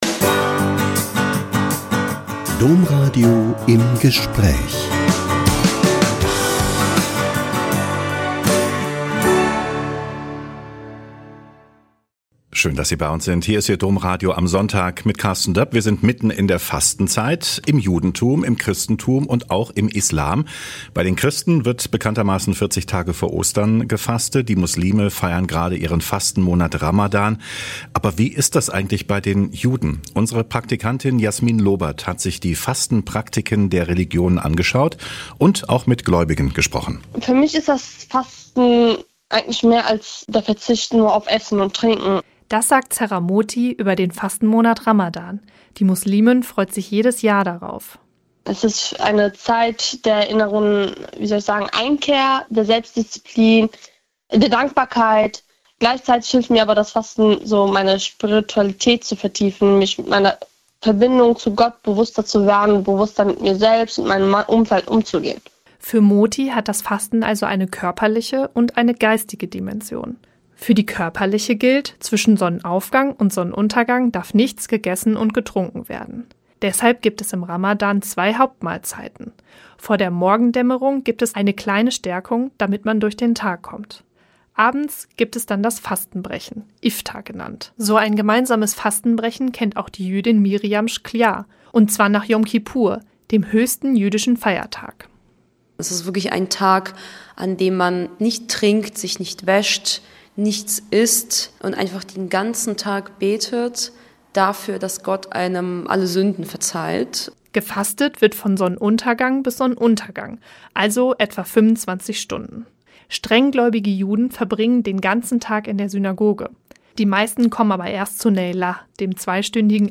Drei junge Menschen